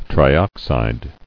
[tri·ox·ide]